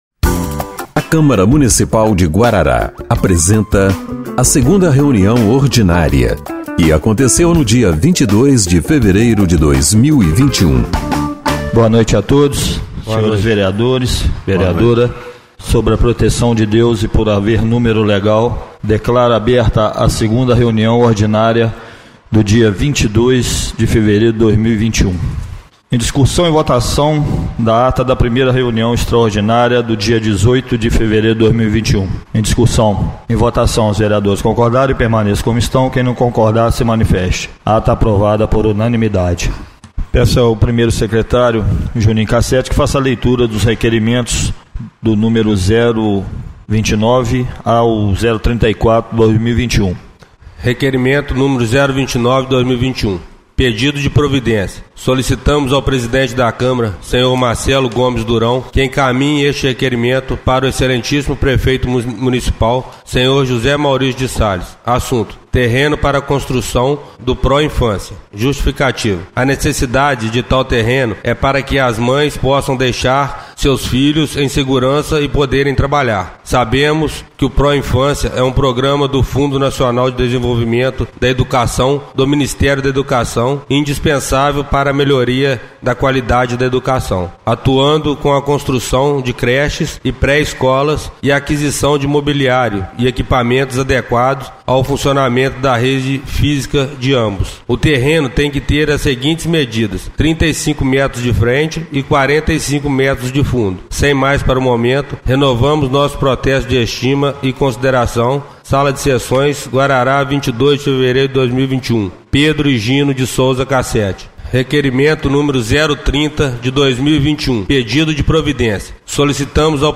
2ª Reunião Ordinária de 22/02/2021 — Câmara Municipal